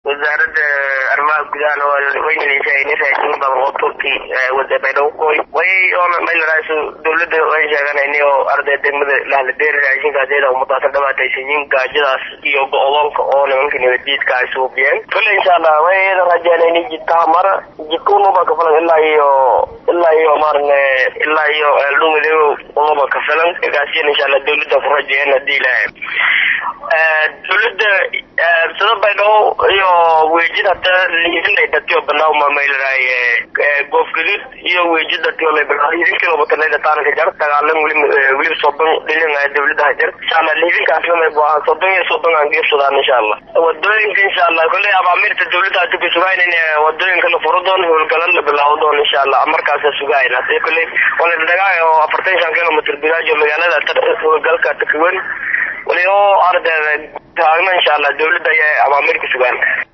Dhegeyso Gudoomiyaha Degmada Waajid oo Ka Hadlaya Dhibaatooyinka Hesyta Shacabka Ku Nool Degmada